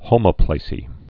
(hōmə-plāsē, -plăsē, hŏmə-)